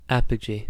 Ääntäminen
IPA : /ˈæpədʒi/